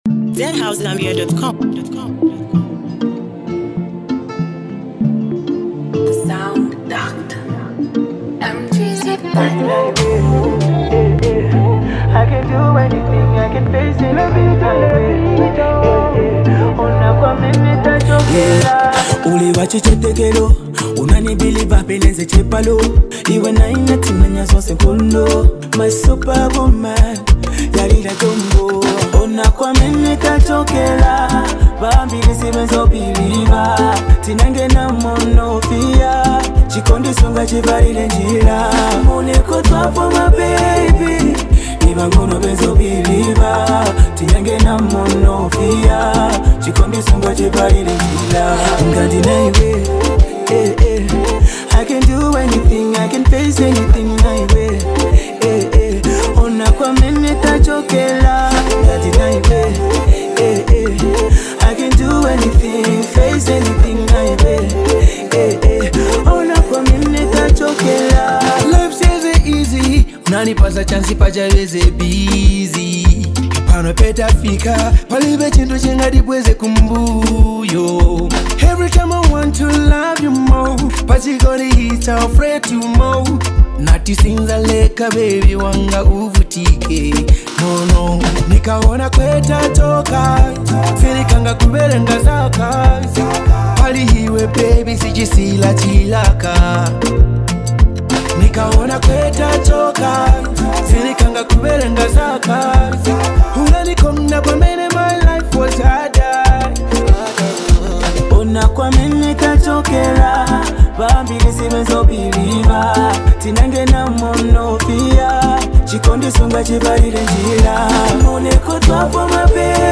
love jam